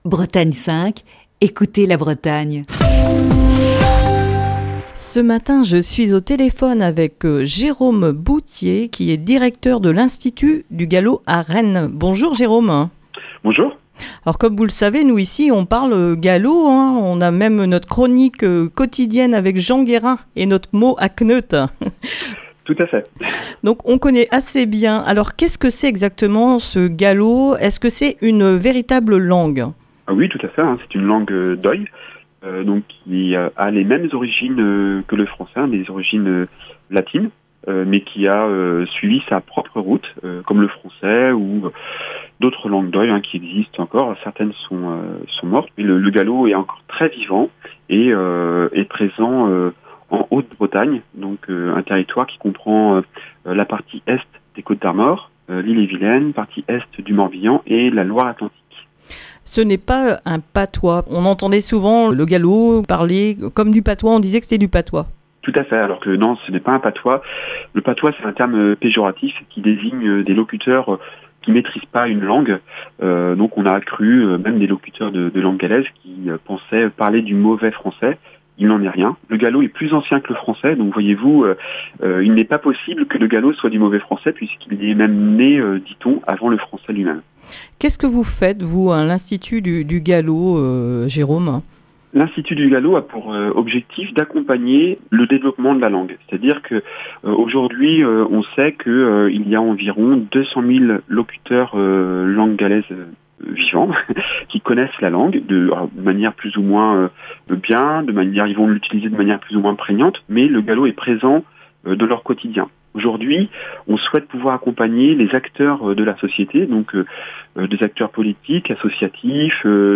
Émission du 5 octobre 2020. Ce lundi, il est question des formations pour apprendre le gallo dans le Coup de Fil du matin.